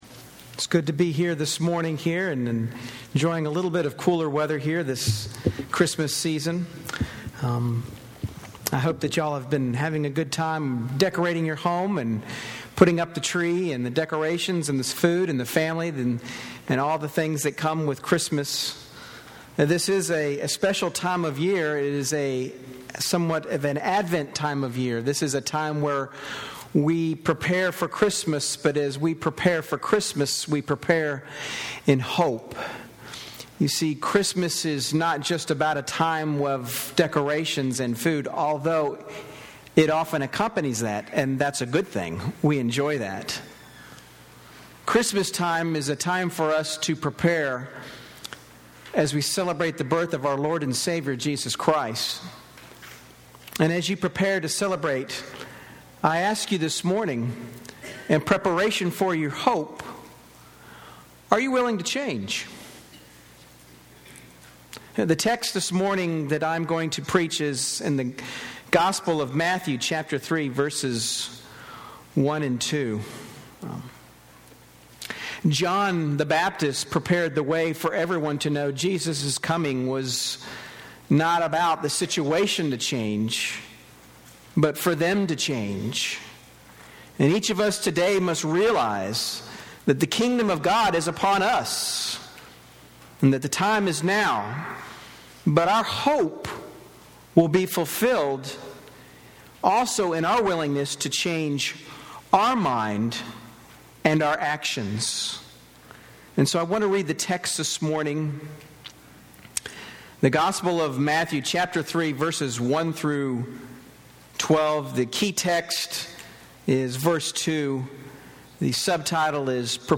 While we prepare to celebrate Christmas let us also prepare to hope, but be willing to change. The text for this sermon is Matthew 3:1-12.